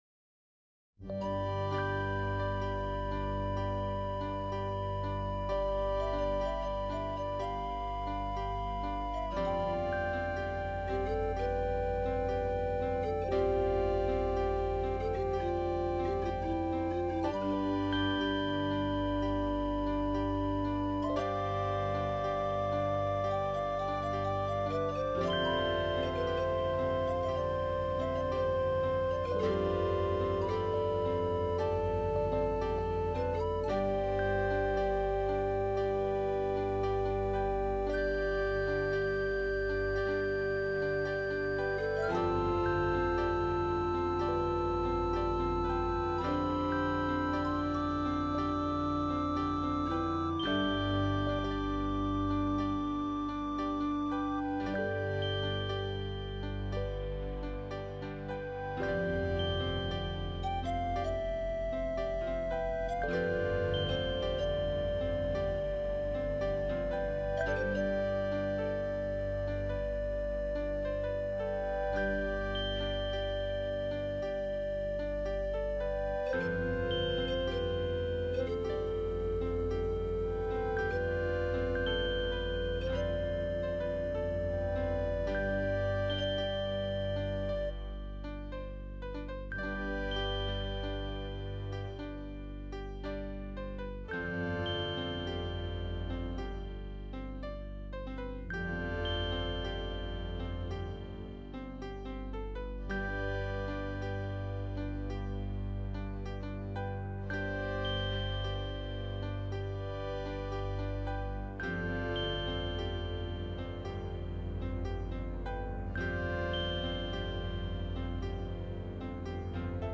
BGM Music